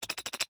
rabbit-v1.ogg